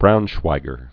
(brounshwīgər)